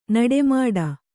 ♪ naḍe māḍa